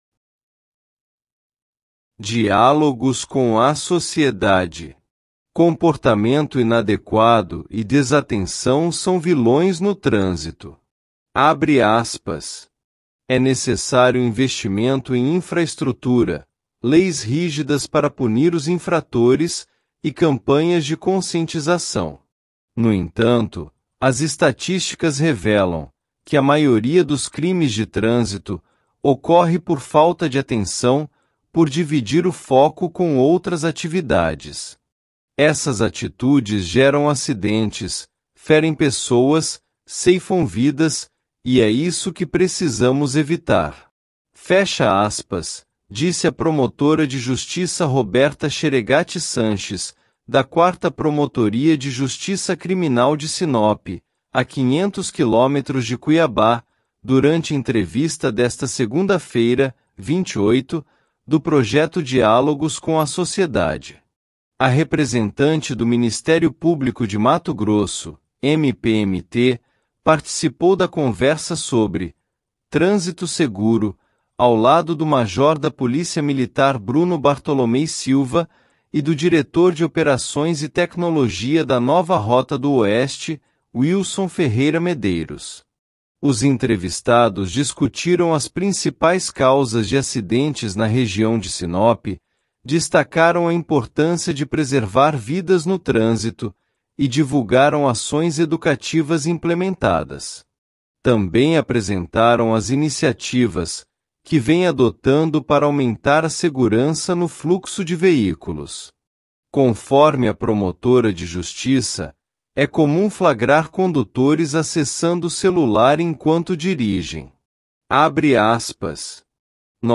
Os entrevistados discutiram as principais causas de acidentes na região de Sinop, destacaram a importância de preservar vidas no trânsito e divulgaram ações educativas implementadas. Também apresentaram as iniciativas que vêm adotando para aumentar a segurança no fluxo de veículos.